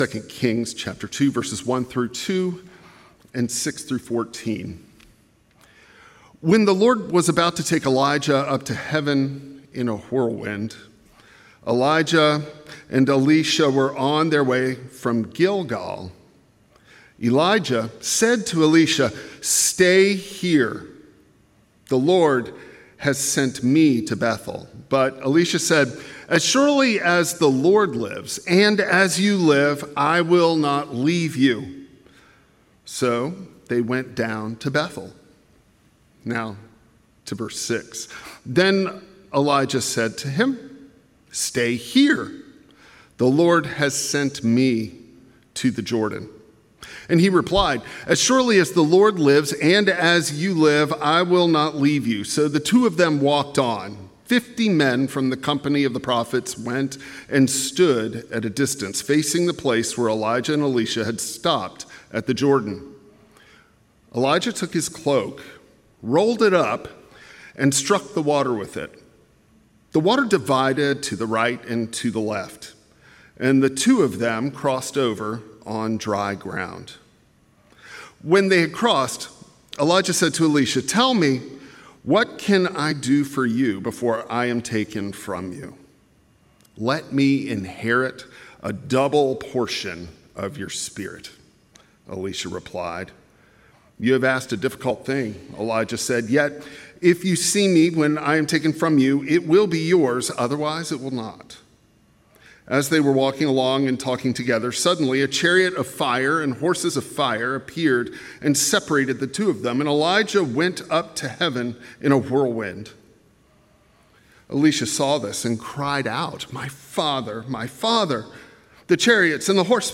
6-14 Service Type: Traditional Service Ministry is tradition passed in love.